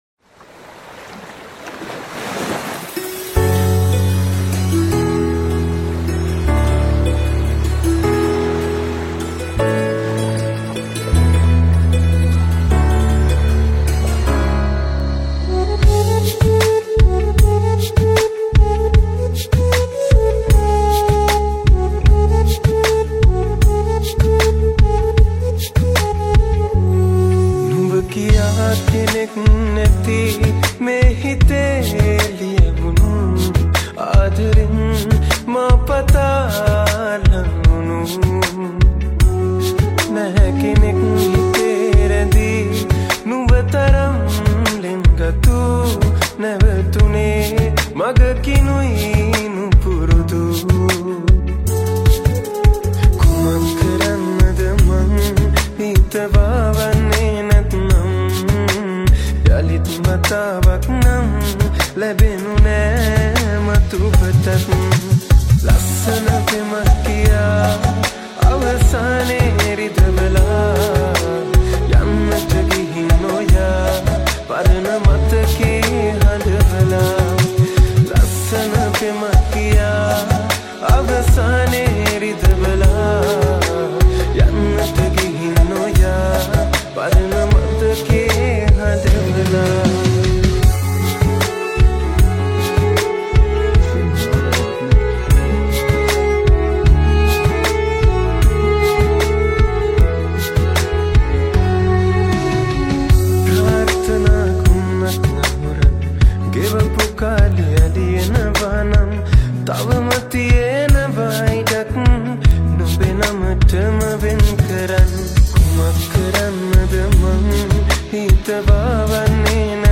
Guitar (solo)